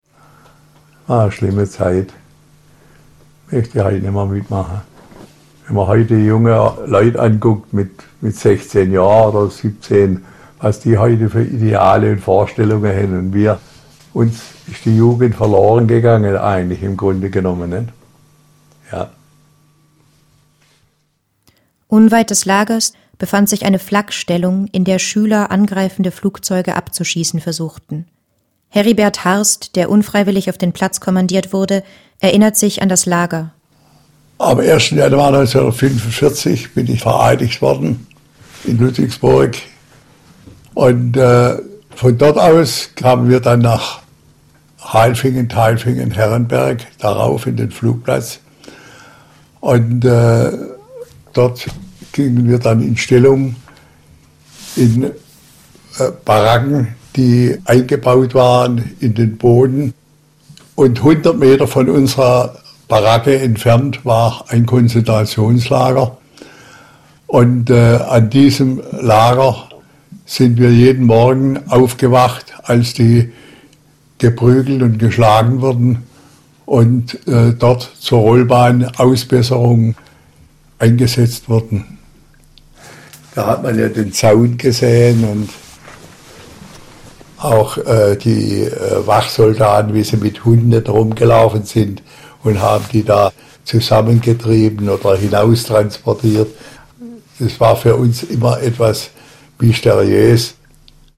Audioguide Titel 4
mittlere Audioqualität